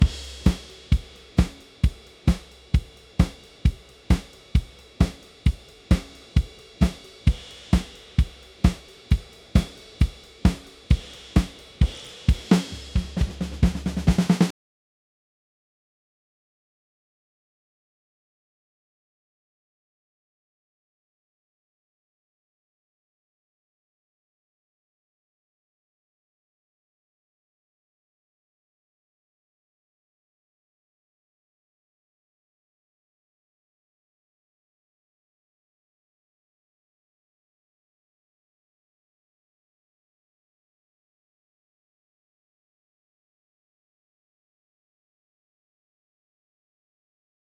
A model that transforms rhythmic audio inputs into professional drum performances by applying a multi-stage generative process, producing four unique variations per input.
• Converts diverse rhythmic sources (drums, beatboxing, body percussion) into studio-quality drum tracks